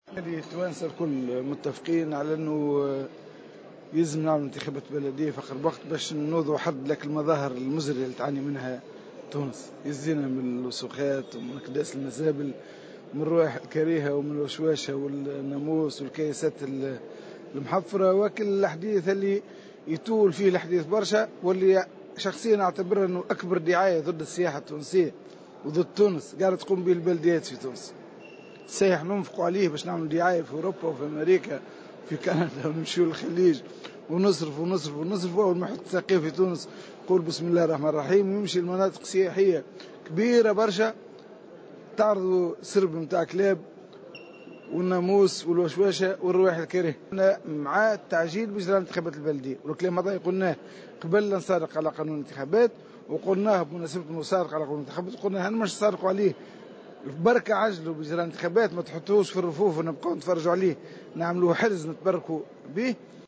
وأضاف في تصريح لمراسل "الجوهرة أف أم" على هامش لقاء جمع اليوم الثلاثاء اعضاء الهيئة العليا المستقلة للانتخابات بممثلي عدد من الأحزاب أن حركة النهضة تدعم مقترح الهيئة لإجراء الانتخابات في الموعد الذي حدّدته (26 نوفمبر 2017).